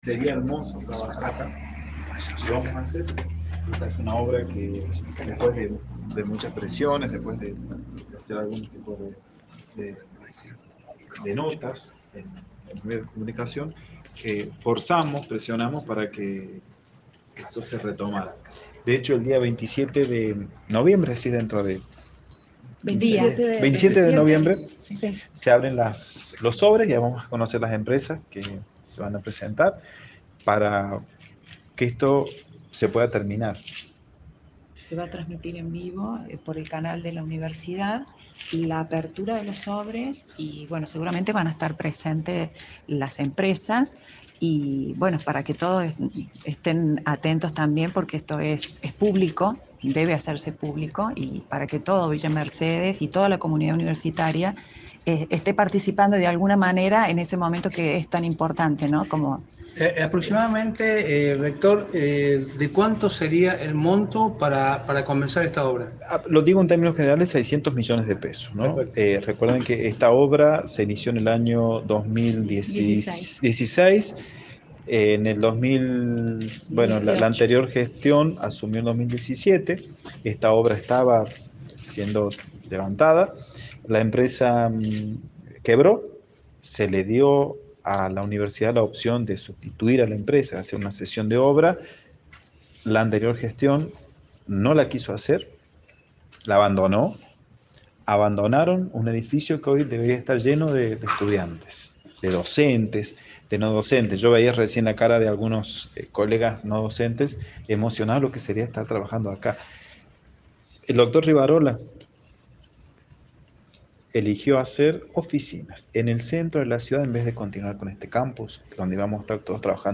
Lo hizo acompañado por funcionarios de la Secretaría de Infraestructura y periodistas locales, para mostrar el estado de abandono en que lo encontró y las características generales que tendrá el nuevo complejo.
Visita-al-campus.mp3